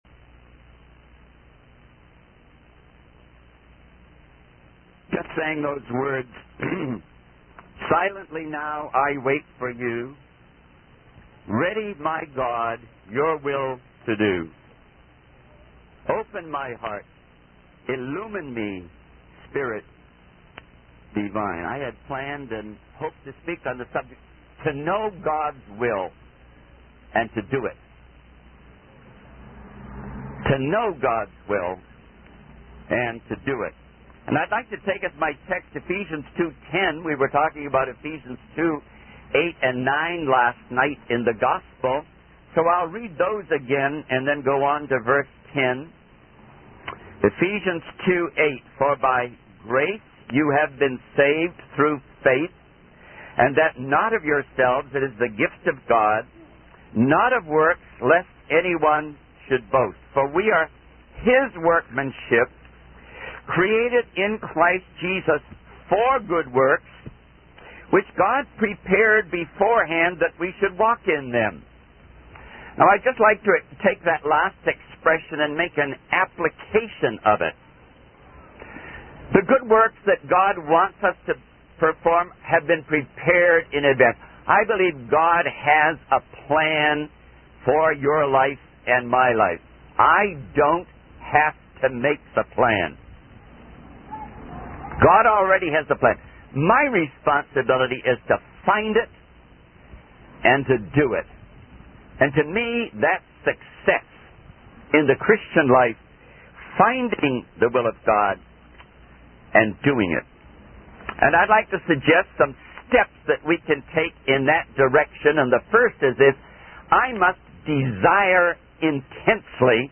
In this sermon, the speaker emphasizes the importance of seeking God's guidance in making decisions. He encourages listeners to trust that God has better plans for them than they can imagine. The speaker shares personal stories and biblical references to illustrate how God guides and directs His people.